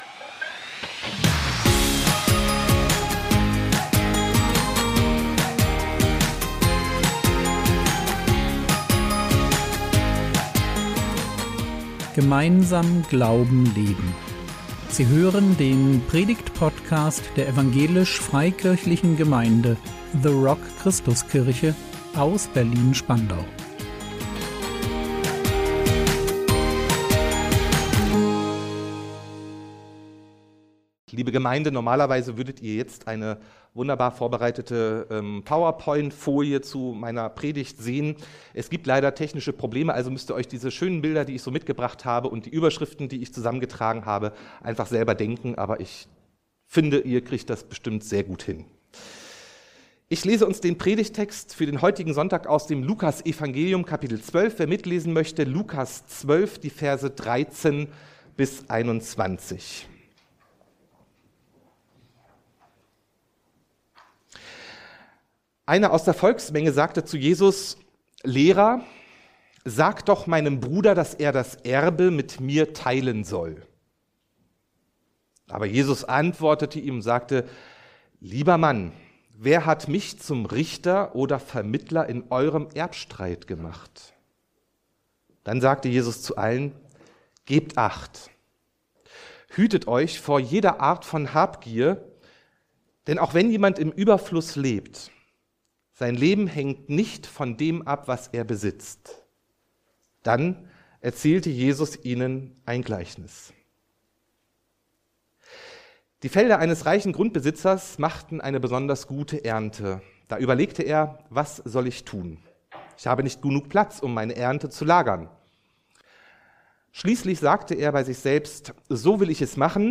Was wirklich wichtig ist | 10.11.2024 ~ Predigt Podcast der EFG The Rock Christuskirche Berlin Podcast